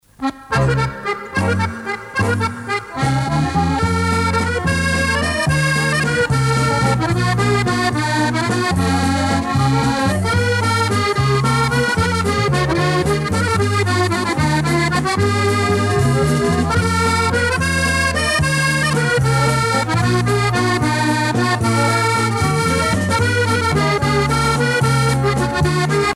danse : valse musette
Pièce musicale éditée